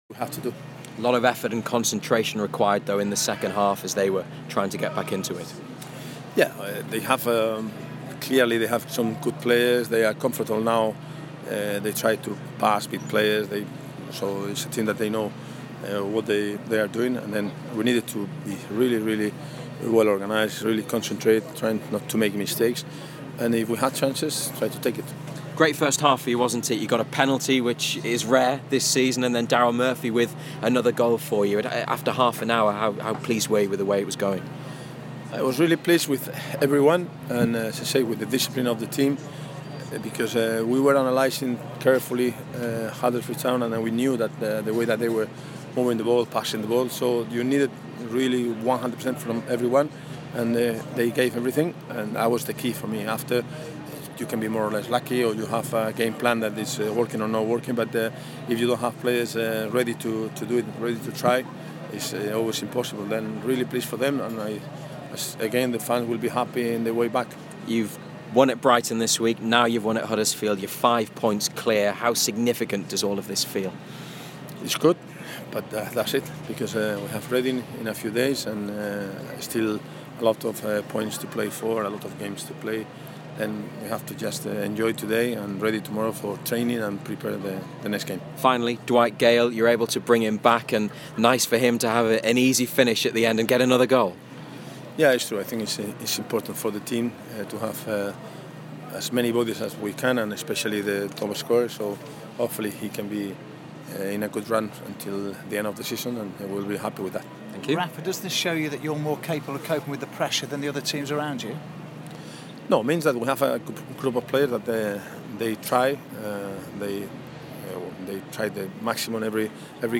Rafa Benítez spoke to BBC Newcastle and assembled media after the Magpies won 3-1 at the John Smith's Stadium.